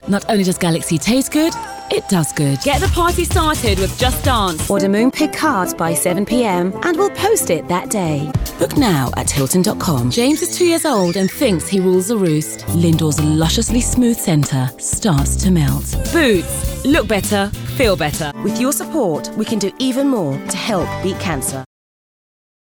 20 Second Sound Bite
Female
Neutral British
Multicultural London English (MLE)
British RP
Bright
Confident
Smooth
Youthful